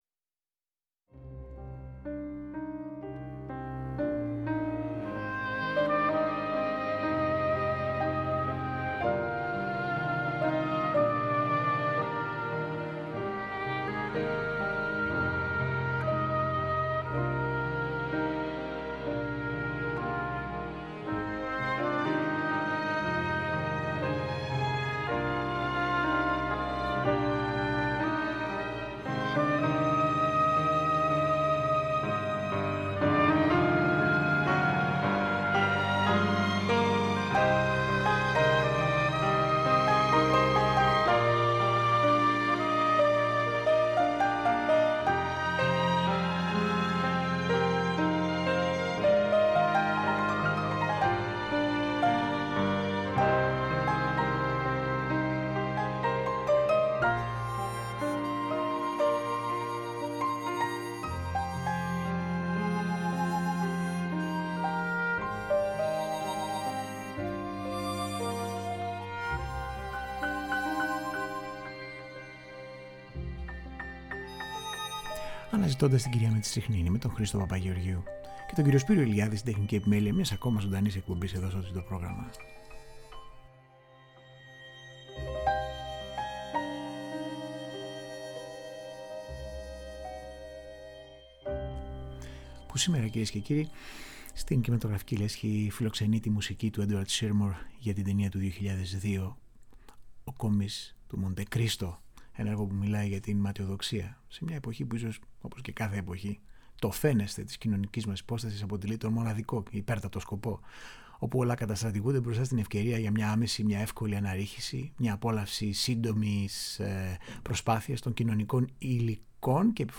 Η όμορφη και συναρπαστική μουσική